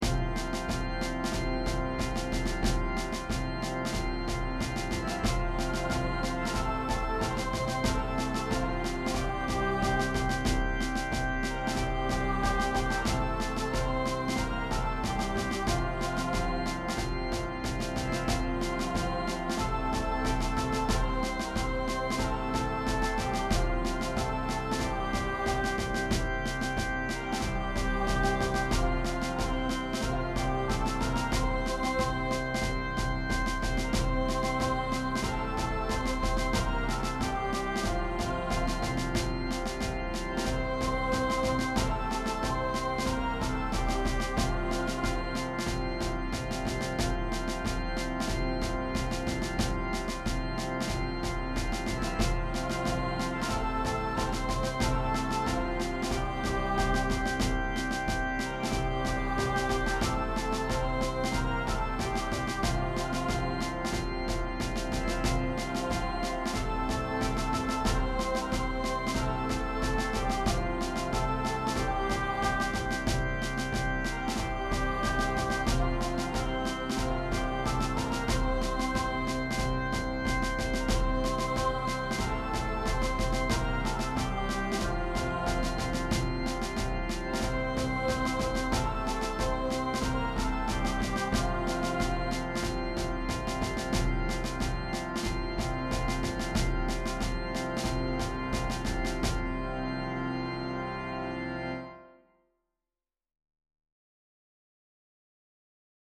Choir Unison, Organ/Organ Accompaniment, Percussion
Voicing/Instrumentation: Choir Unison , Organ/Organ Accompaniment , Percussion We also have other 3 arrangements of " Come We That Love the Lord ".
Simplified Arrangement/Easy Play